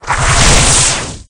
Wind5.ogg